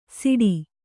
♪ siḍi